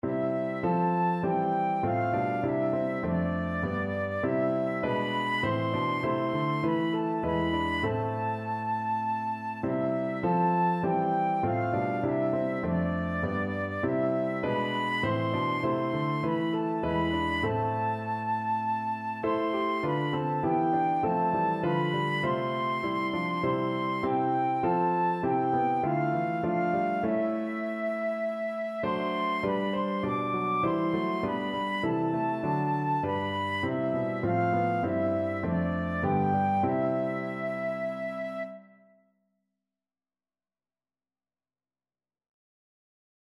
4/4 (View more 4/4 Music)
D6-D7
Classical (View more Classical Flute Music)